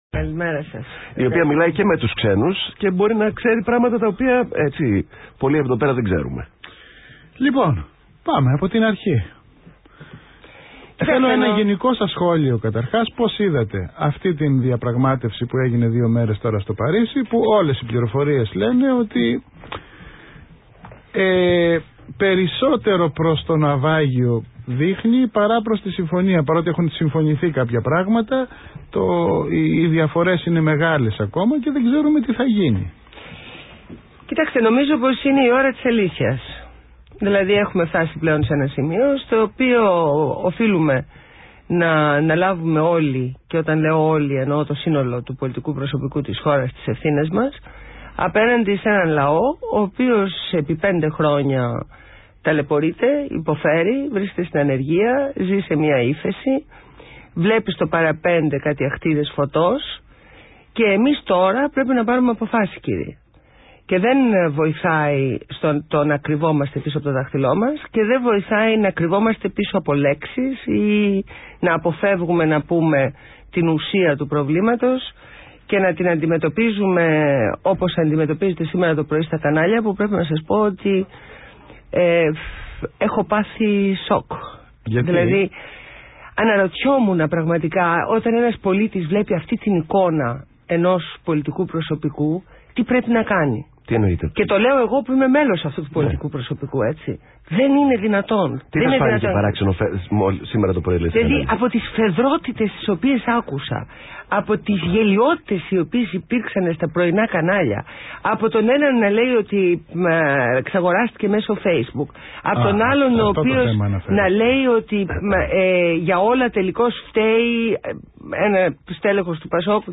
Συνέντευξη στο ΒΗΜΑfm